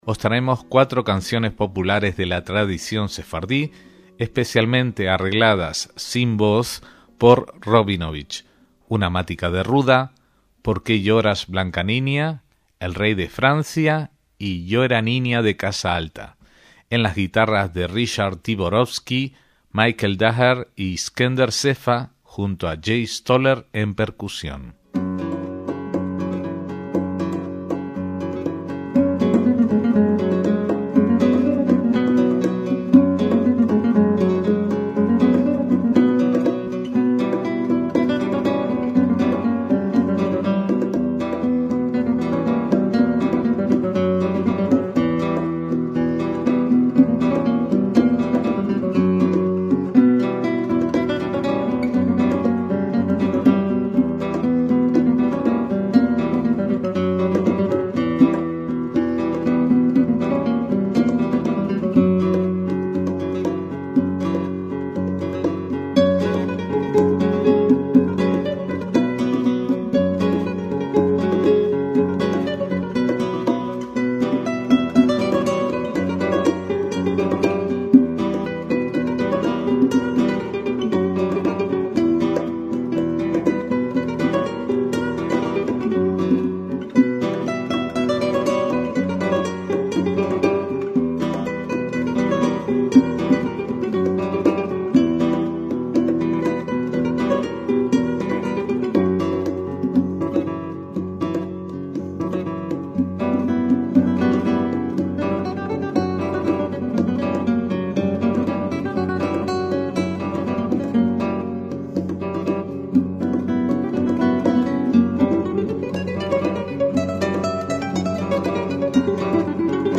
MÚSICA SEFARDÍ